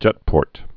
(jĕtpôrt)